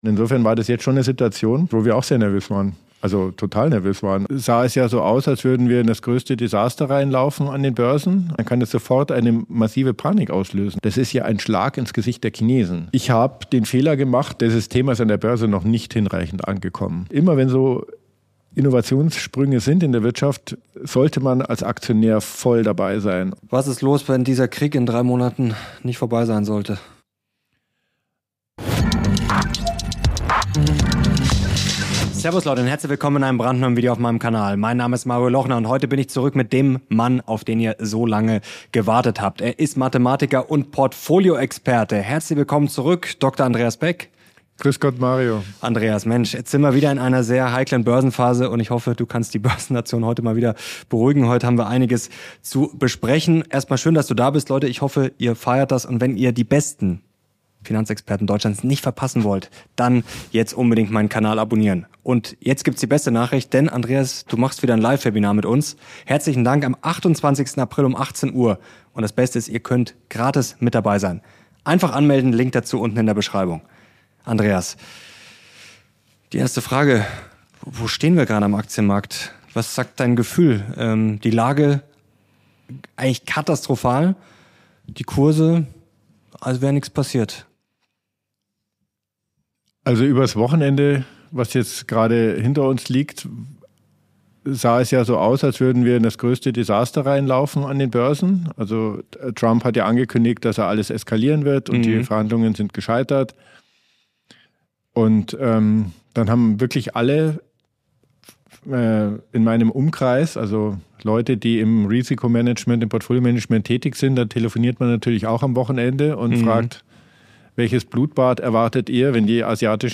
Dieses Interview ist Pflicht für alle, die wissen wollen, wie sie ihr Depot in unsicheren Zeiten aufstellen sollten und worauf die Märkte gerade womöglich falsch blicken.